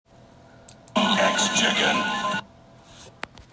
Sound Bytes from the Konami X-men Video Game